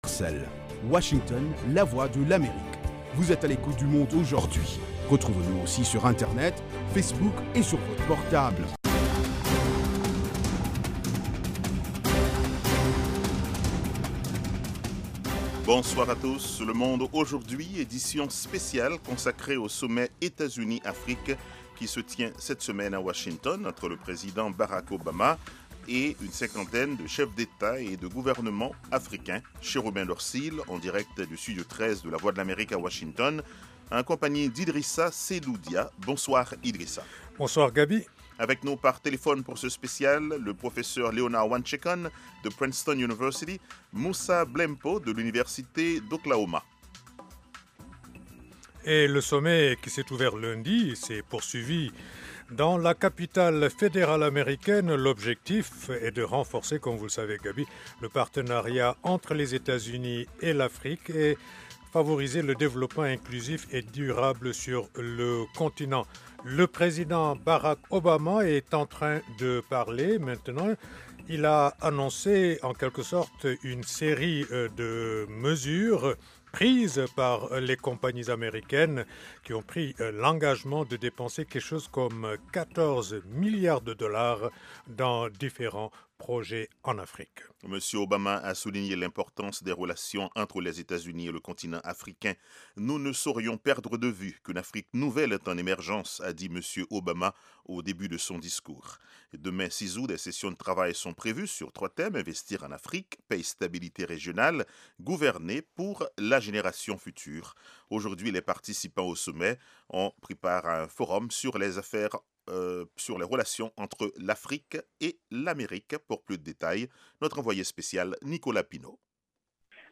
Toute l’actualité sous-régionale sous la forme de reportages et d’interviews. Des dossiers sur l'Afrique etle reste du monde. Le Monde aujourd'hui, édition pour l'Afrique de l’Ouest, c'est aussi la parole aux auditeurs pour commenter à chaud les sujets qui leur tiennent à coeur.